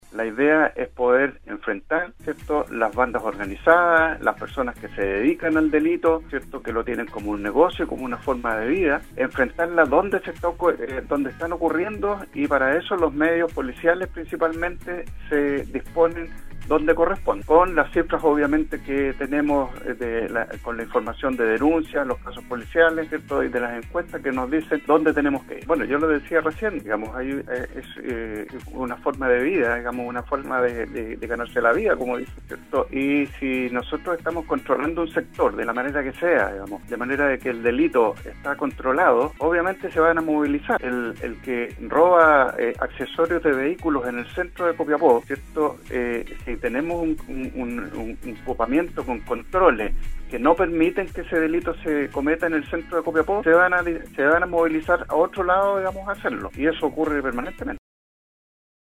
La mañana de este jueves, el Coordinador Regional de Seguridad Pública, Arturo Urcullu Clementi, sostuvo un contacto en el programa Al Día de Nostálgica donde se refirió a la disminución de los delitos en Atacama según la Encuesta Nacional de Seguridad.